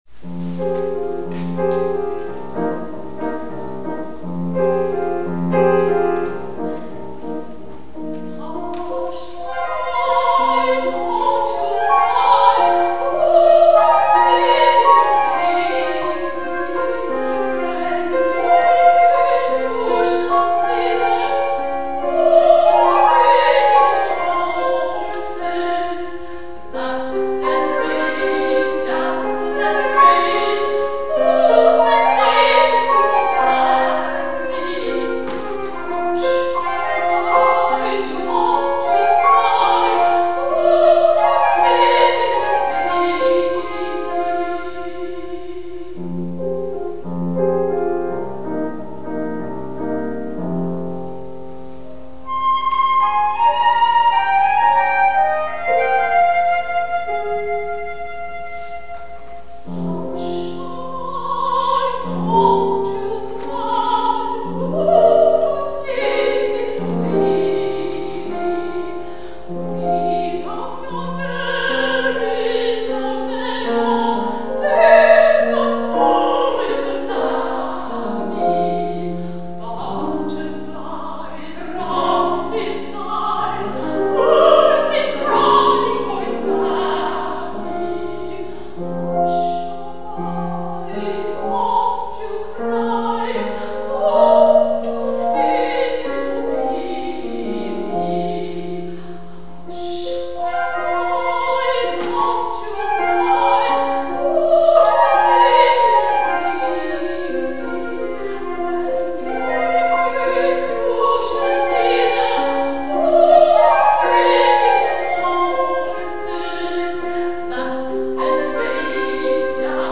MUSIC AT BELL STREET CHAPEL Recorded in concert at Bell St. Chapel, Providence, RI
flute
soprano
piano